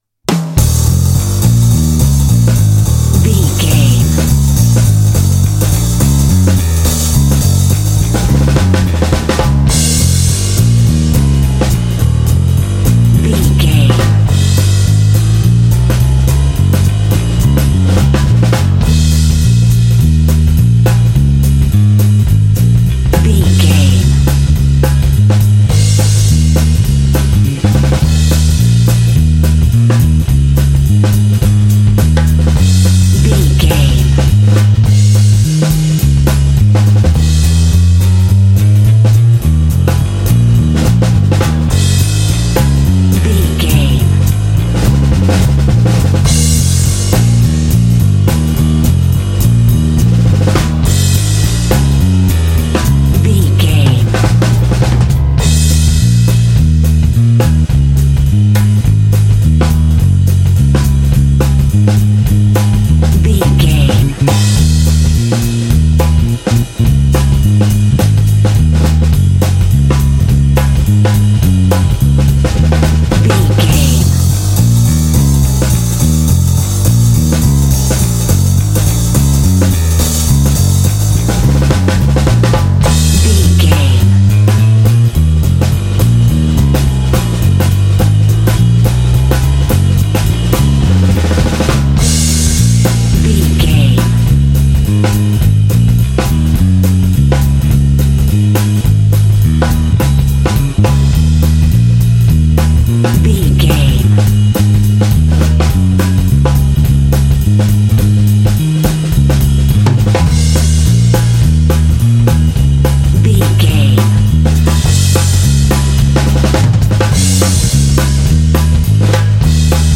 Aeolian/Minor
E♭
energetic
groovy
lively
bass guitar
piano
jazz
big band